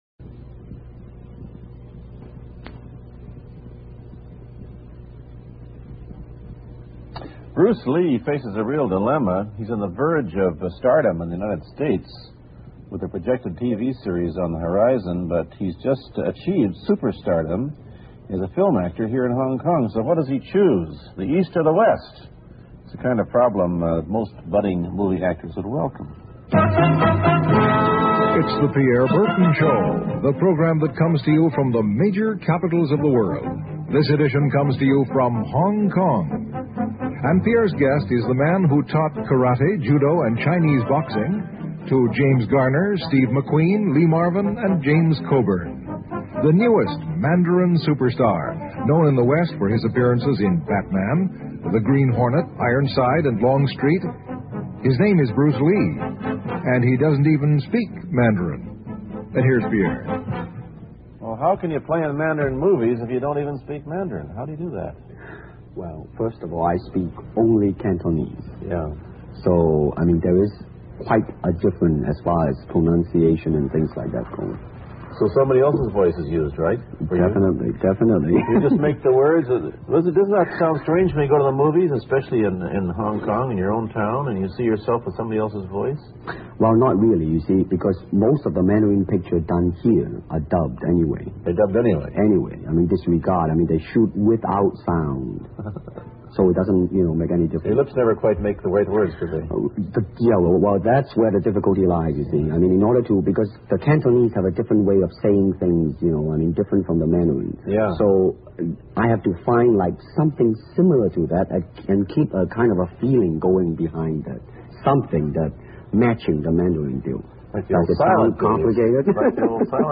西部落：采访李小龙-1 听力文件下载—在线英语听力室